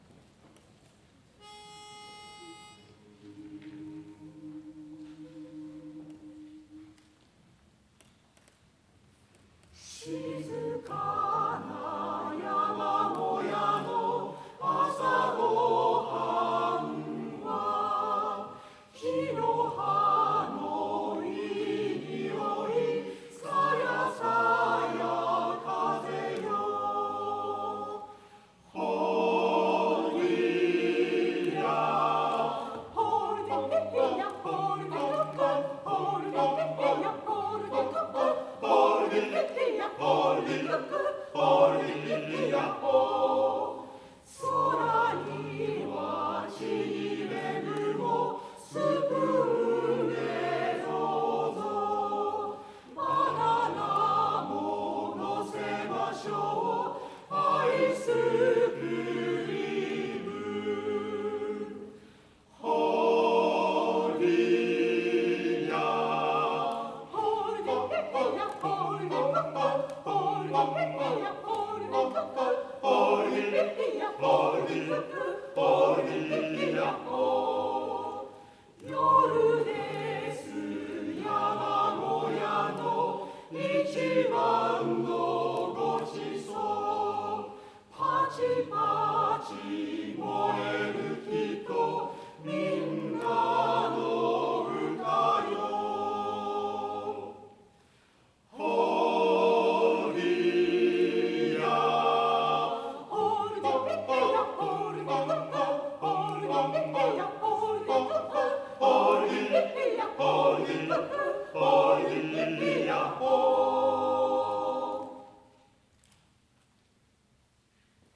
第39回野田市合唱祭
野田市文化会館
山のごちそう　詞：阪田寛夫　オーストリア民謡／